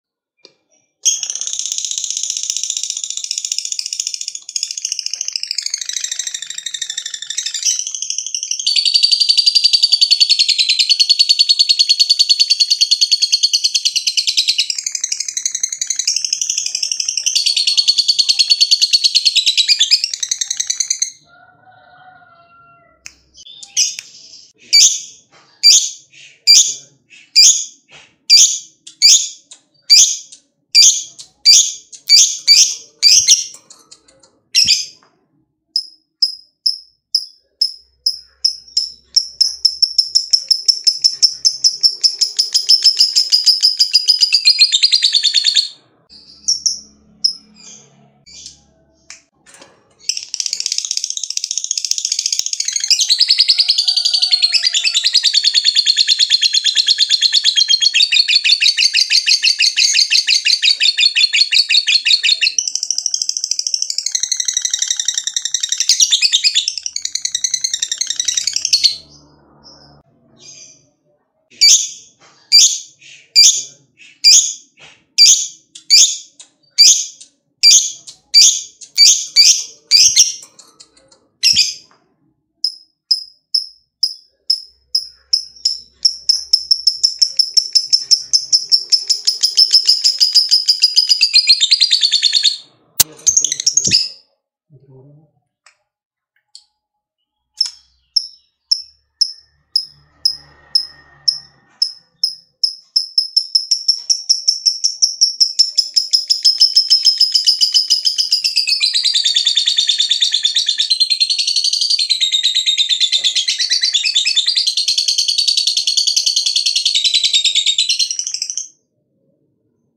Suara Lovebird Betina
Kategori: Suara burung
Keterangan: Suara Burung Lovebird Betina Birahi MP3 – Begini suara lovebird betina saat birahi, bikin emosi lawan terpancing!
suara-lovebird-betina-id-www_tiengdong_com.mp3